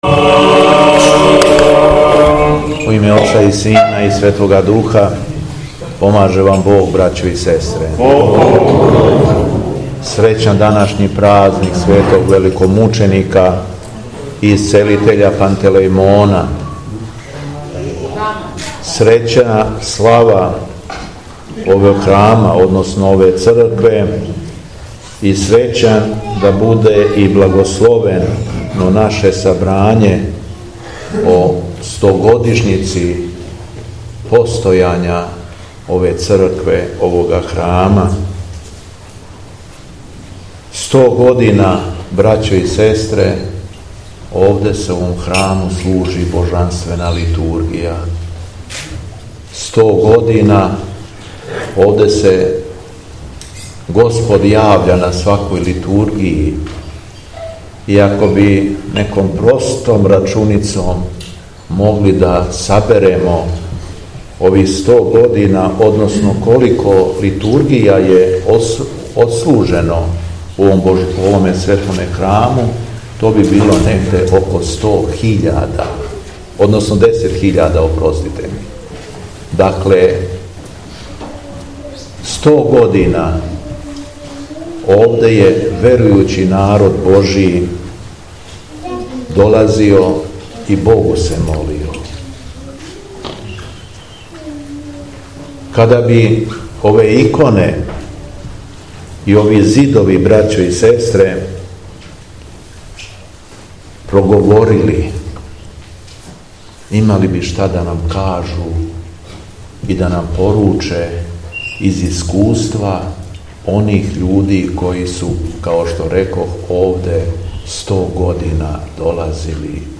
Беседа Његовог Преосвештенства Епископа шумадијског г. Јована
Поводом храмовне славе и стогодишњице од освећења храма, 9. августа, 2023. године, када прослављамо Светог Великомученика Пантелејмона, Његово Преосвештенство Епископ шумадијски Господин Јован служио је Свету Архијерејску Литургију у селу Клоки надомак Тополе.